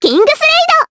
Annette-Vox_Kingsraid_jp.wav